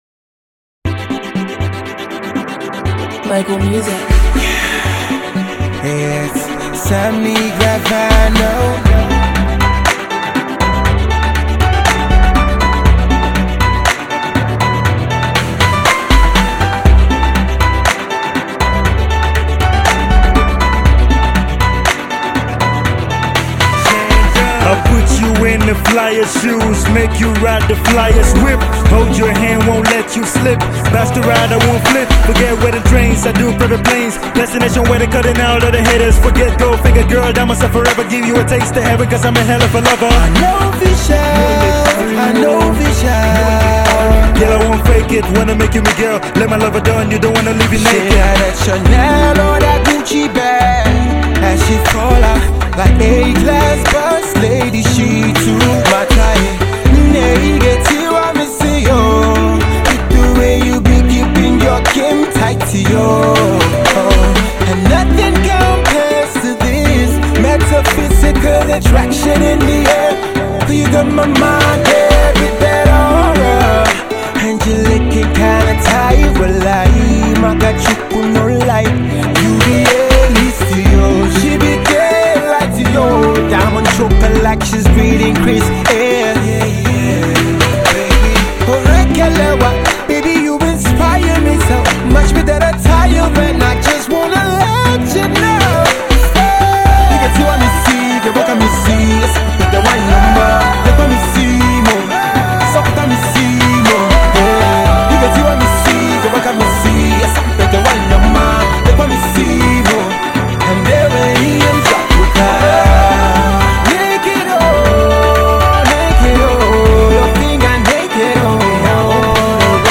the Trey Songz-esque